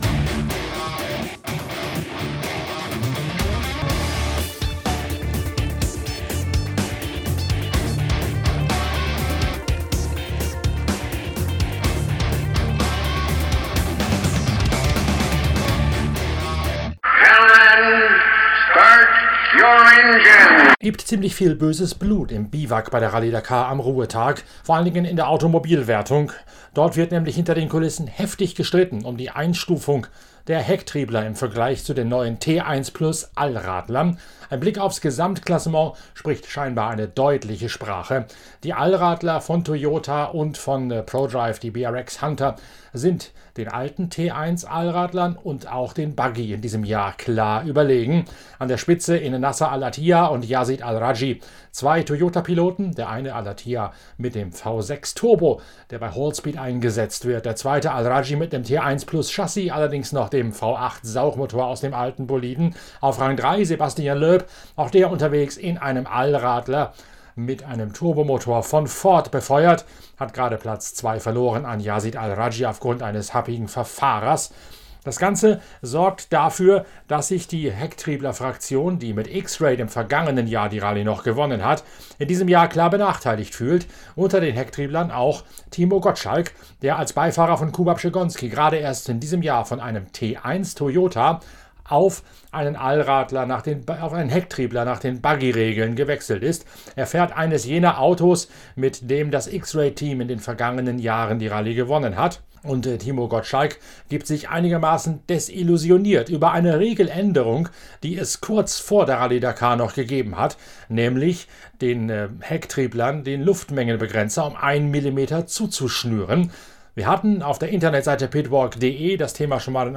Die Motorradwertung steht in der zweiten Dakar-Woche für die größte Spannung. Matthias Walkner und Kevin Benavides analysieren in Schalten live ins KTM-Biwak ihre bisherige Rallye und ihre Herangehensweise für Halbzeit 2.